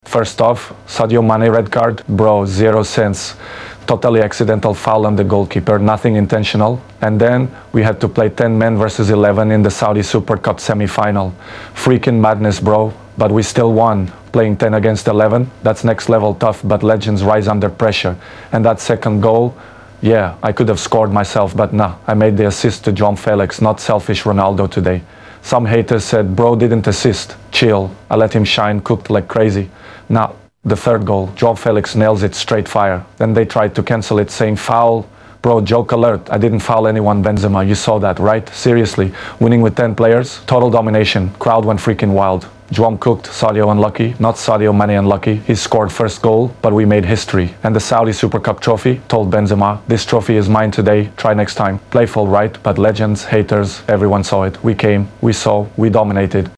Ronaldo interview after beating Benzema’s sound effects free download